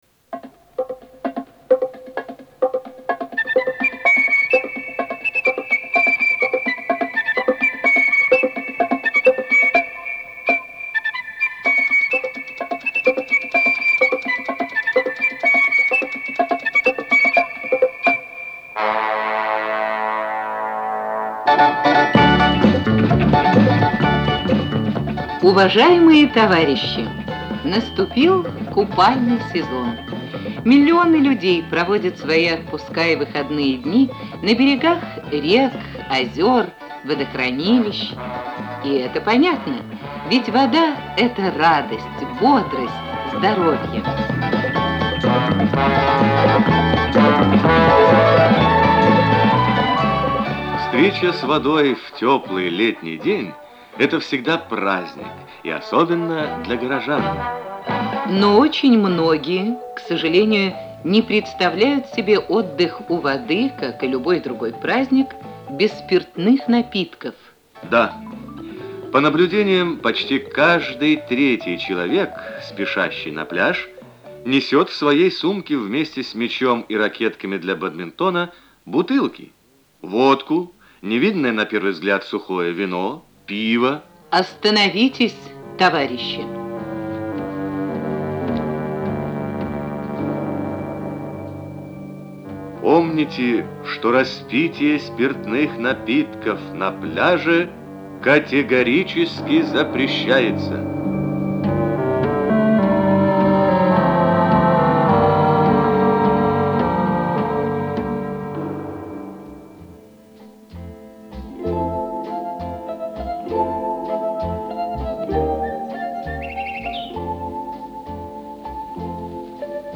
Социальная реклама в СССР